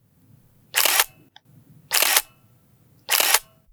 • camera shutter of 35 mm SLR camera.wav
camera_shutter_of_35_mm_SLR_camera_nk9.wav